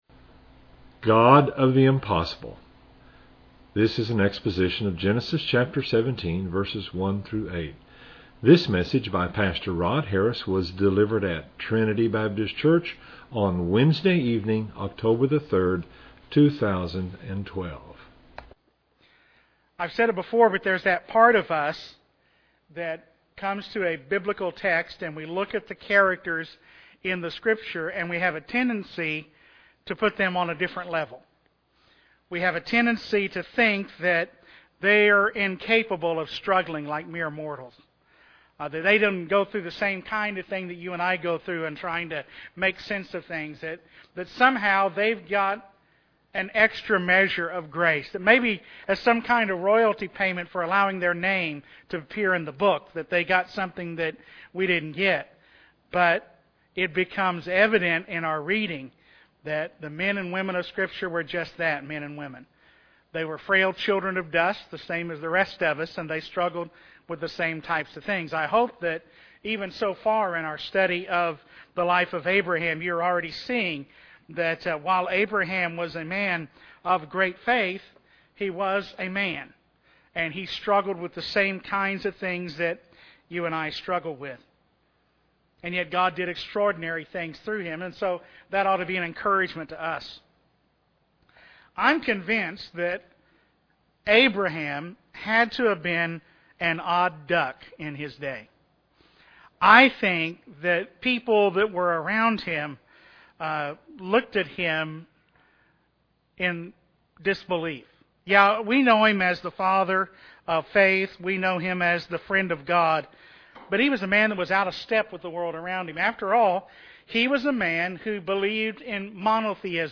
This exposition of Genesis 17:1-8